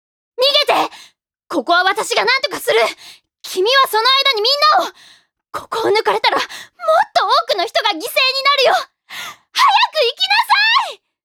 ボイスサンプル
ボイスサンプル２